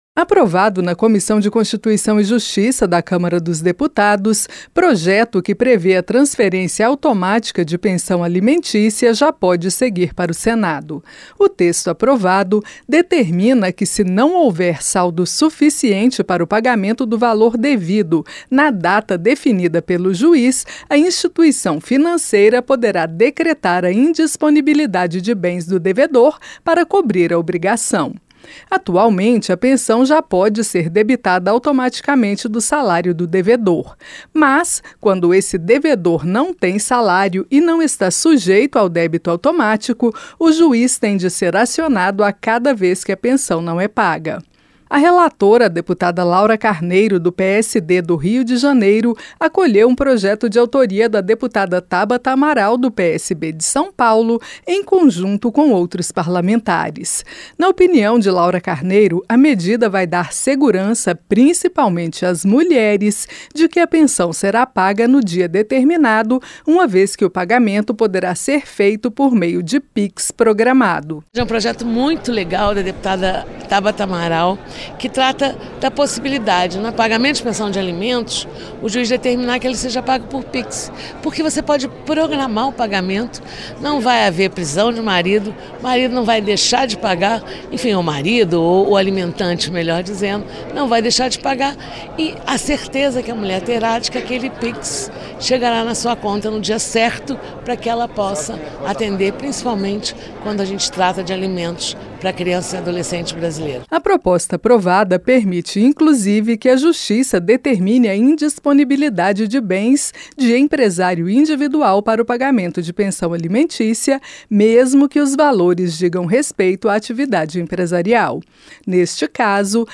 Projeto que permite transferência automática de pensão alimentícia pode seguir para o Senado - Radioagência